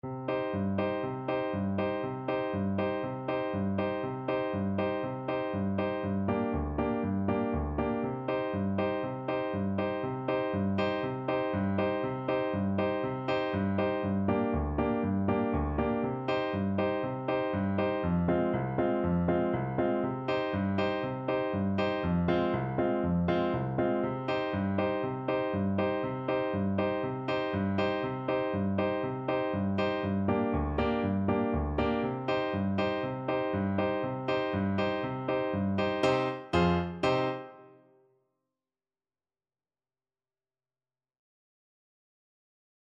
Allegro vivo (View more music marked Allegro)
4/4 (View more 4/4 Music)